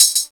81 TAMB.wav